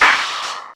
70TECHNOSD-L.wav